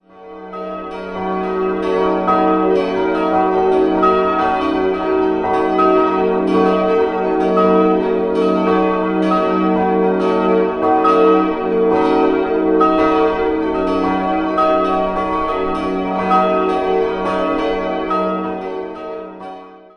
6-stimmiges erweitertes Ges-Dur-Geläute: ges'-b'-des''-es''-ges''-as'' Die Glocken 1 und 6 wurden 1992 von der Firma Bachert gegossen, die Glocken 3 und 4 stammen aus der Gießerei Rincker aus dem Jahr 1955.